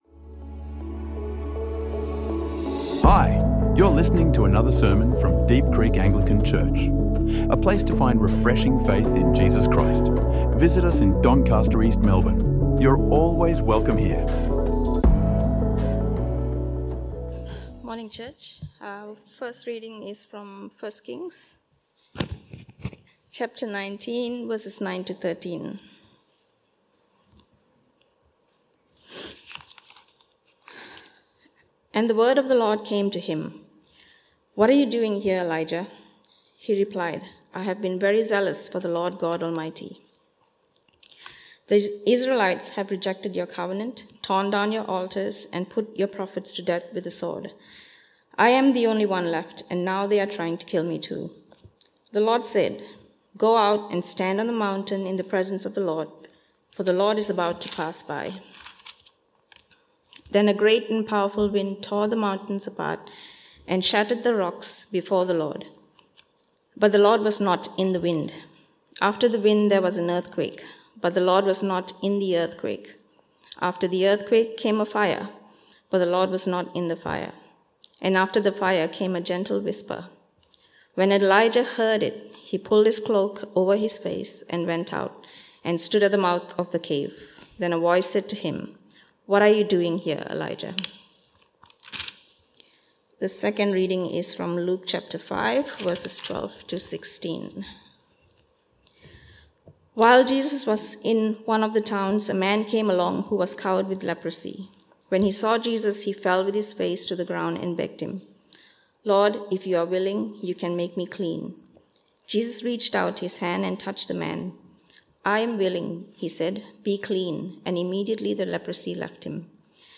Silence and Solitude | Sermons | Deep Creek Anglican Church